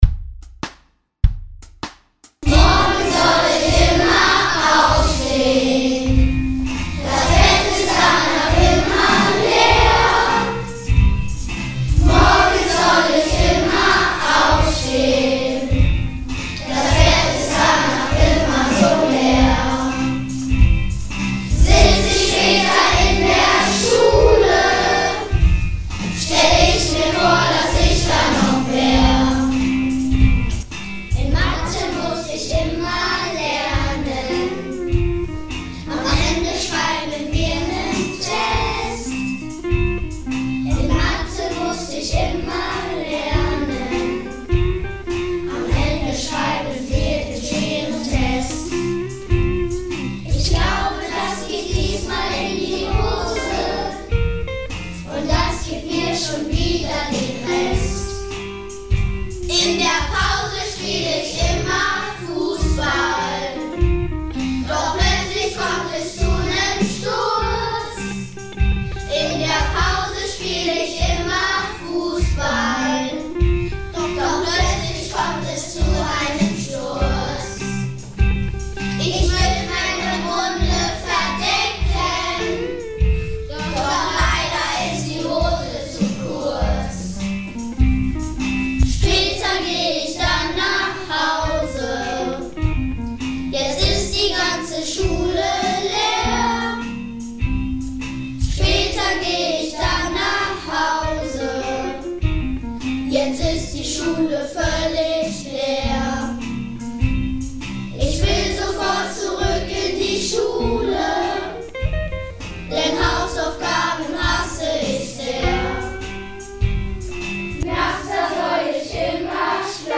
Wir sangen einen und lernten dabei die Form.
Das nahmen wir einzeln auf (jede Klasse für sich).
Mit einem Musikprogramm wurde das anschließend zusammengefügt.
Jetzt klingt es so, als ob die vier Klassen gemeinsam die erste Strophe gesungen hätten und dann gleich jede Klasse ihre Strophe hintendran.
Die "Einwürfe" der Gitarre habe ich anschließend zu Hause eingespielt.
Schulblues mit allen Kindern der dritten und vierten Klassen